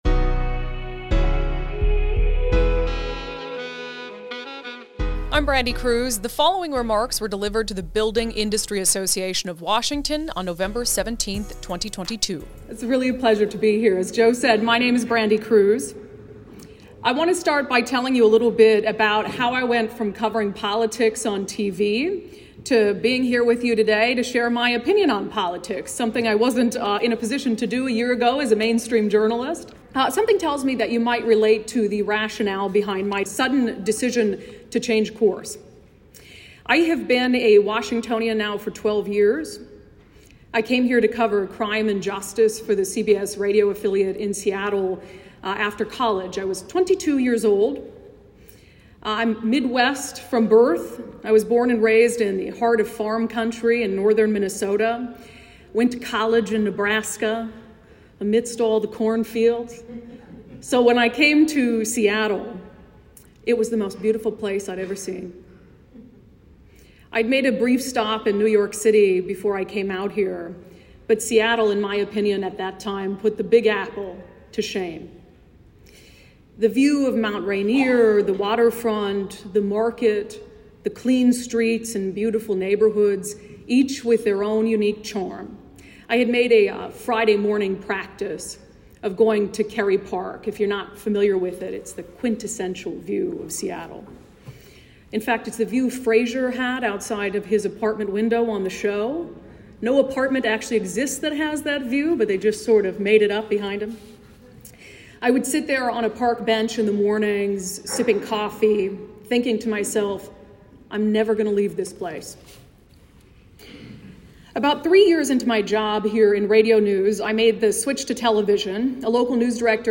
'No common ground without common sense': Remarks to the BIAW